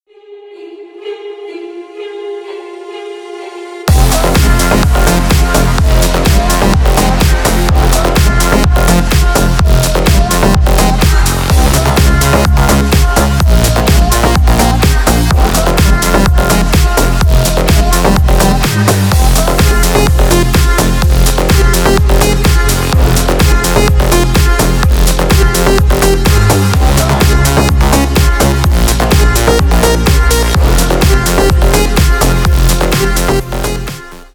Танцевальные
клубные # громкие